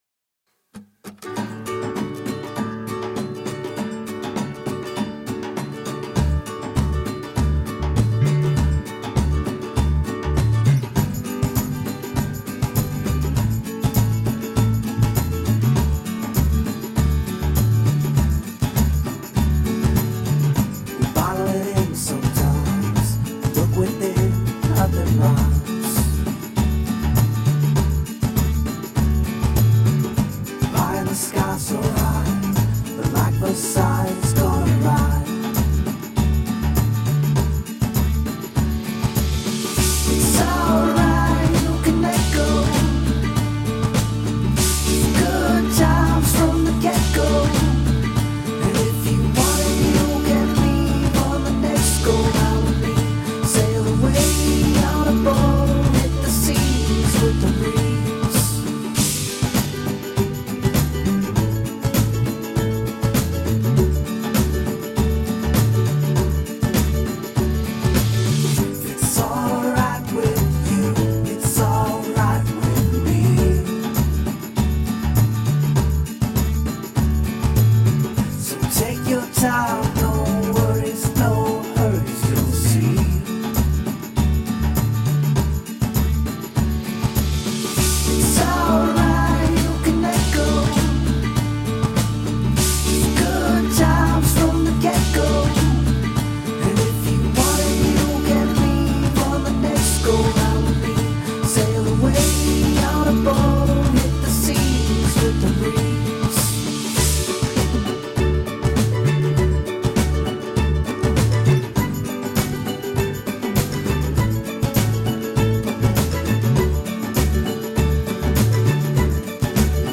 Uptempo, happy, banjo-driven acoustic folk pop.
Features a lot of ukulele, banjo, and acoustic guitars.
Tagged as: Alt Rock, Folk, Folk-Rock, Folk